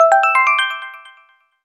sound effect